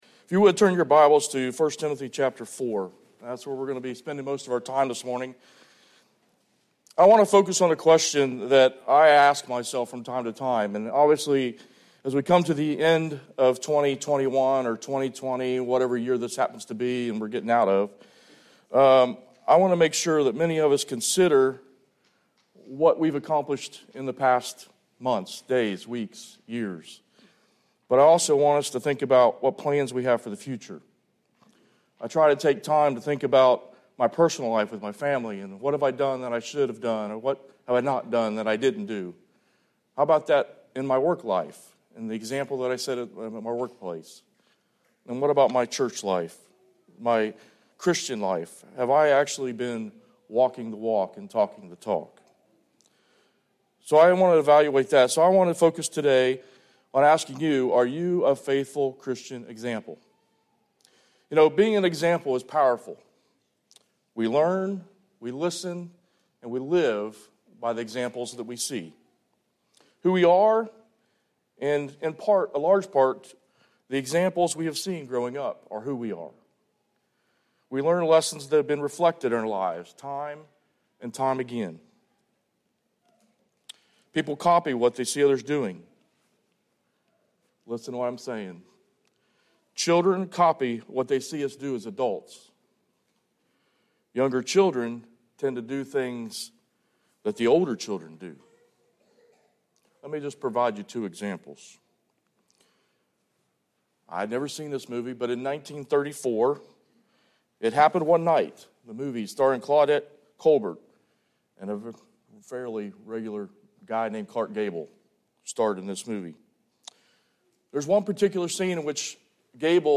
Sermon Archives Passage: 1 Timothy 4:12-16 Service Type: Sunday Morning Worship We're going to be spending most of our time in 1 Timothy chapter four.